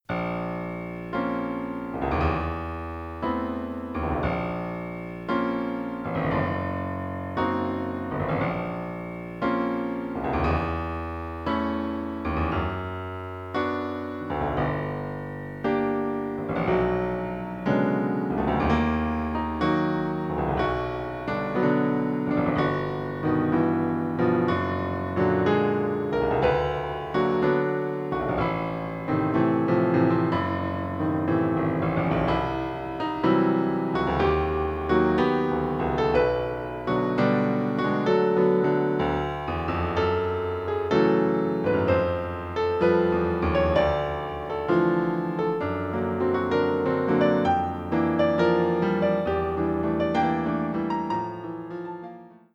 Instrumentation: Piano